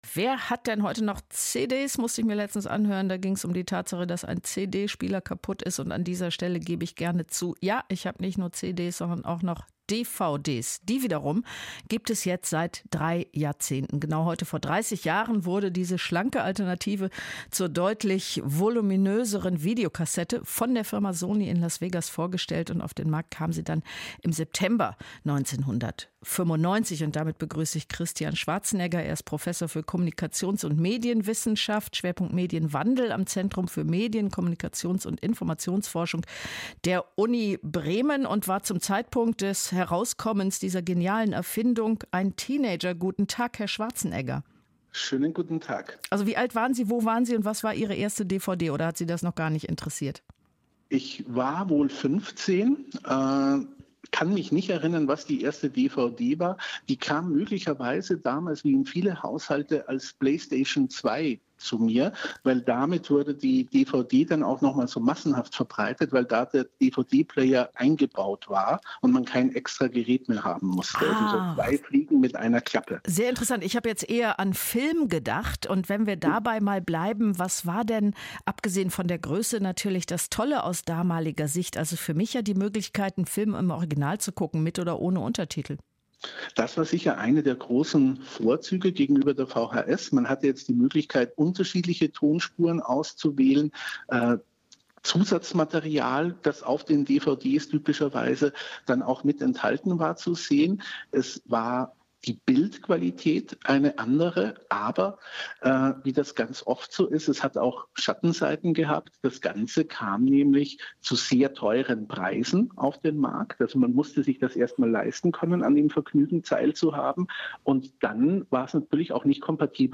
Unter dem Thema „30 Jahre DVD“ ist das Interview am 06.01.2025 auf Bremen Zwei gesendet worden.
Mit freundlicher Genehmigung von Radio Bremen können Sie das Interview hier anhören: